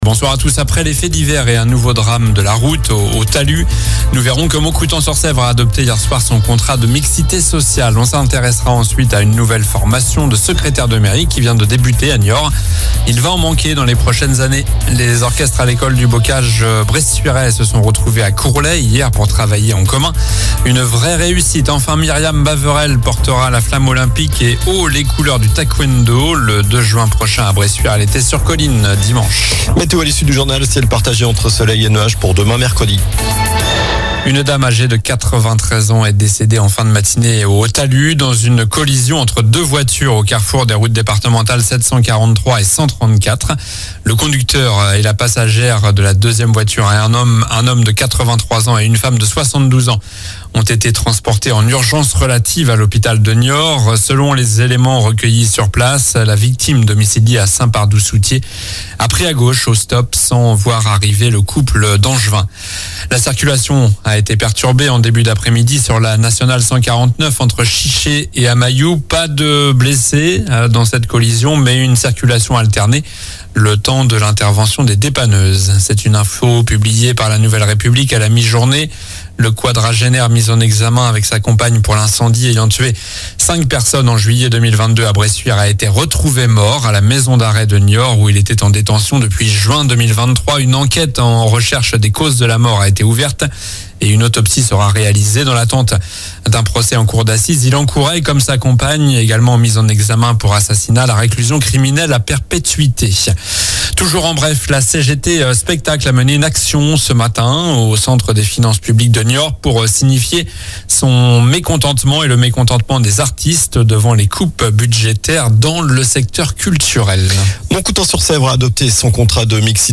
Journal du mardi 9 avril (soir)